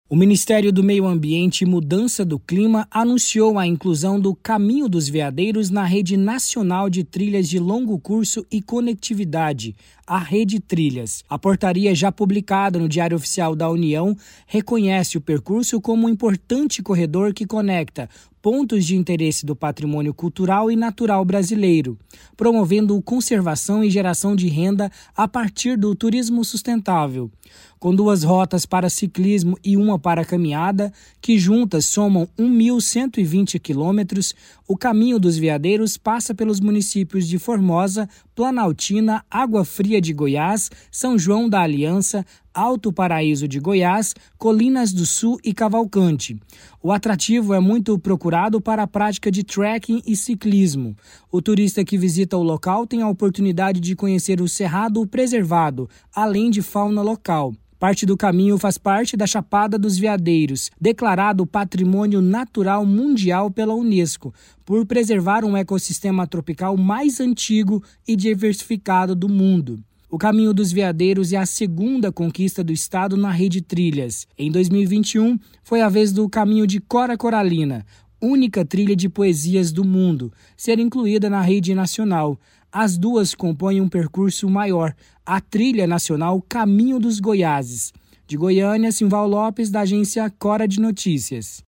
Repórter